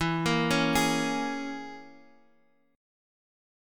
E6b5 Chord